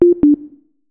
beep_boop.ogg